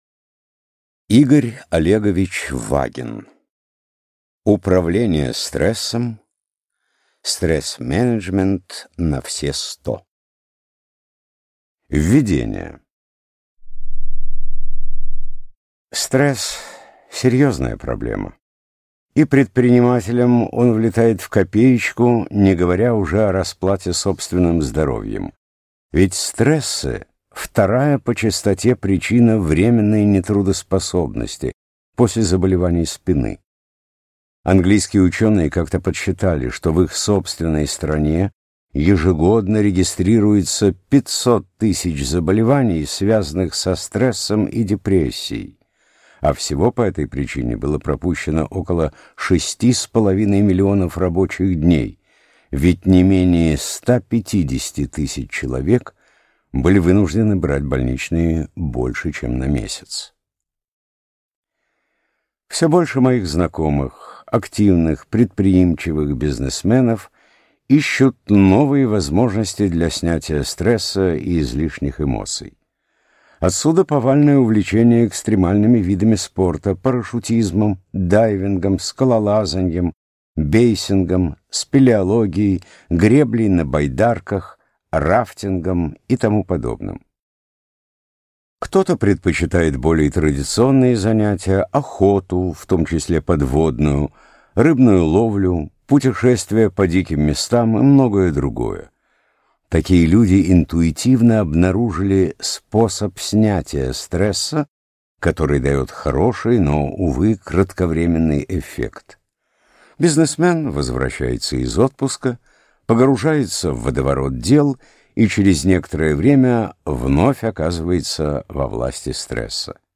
Аудиокнига Управление стрессом | Библиотека аудиокниг